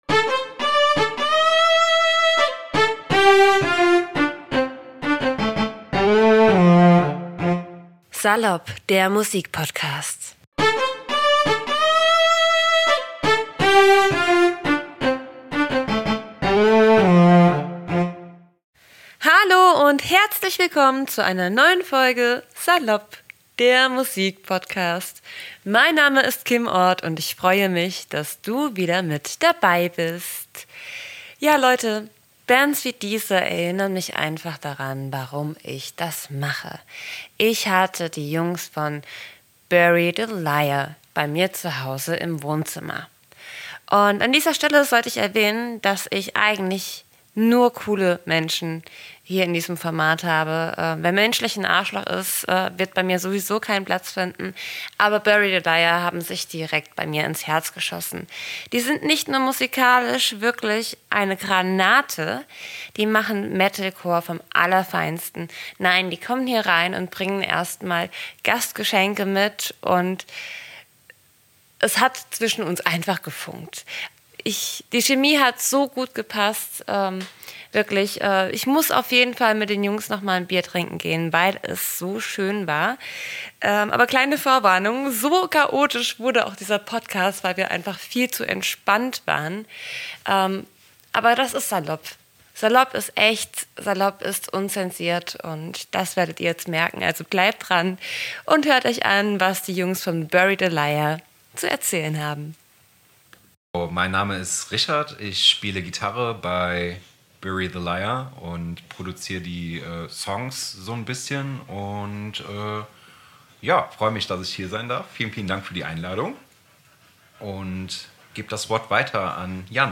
Die Metalcoreband "Bury the Liar" aus Koblenz und Umgebung waren in unserem Salopp-Wohnzimmer und haben mich ordentlich zum Schwitzen gebracht! Neben Fakten und Bassisten-Bashing ging es bei uns ziemlich chaotisch, aber auch verdammt heiß her!